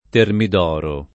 termid0ro] s. m. (stor.)